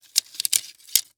household
Plastic Tent Poles Moving Around